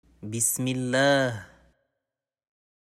Invocation à dire avant de manger